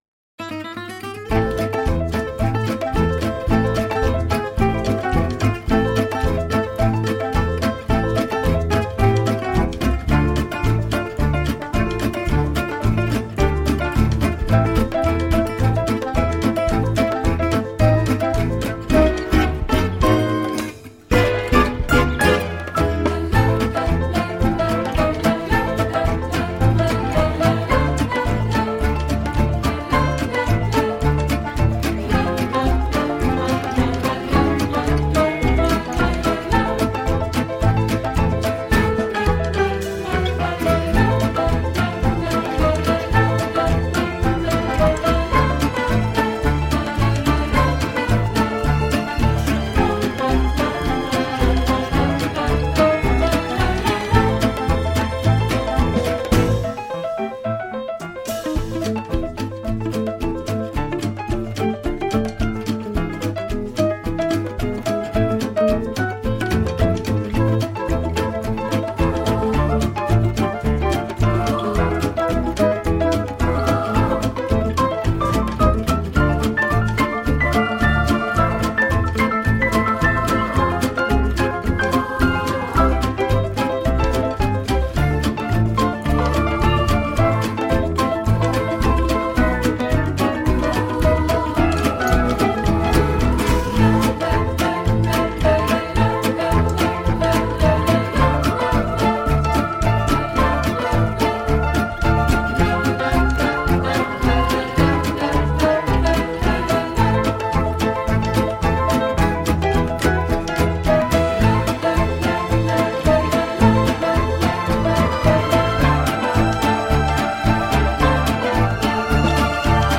avec piano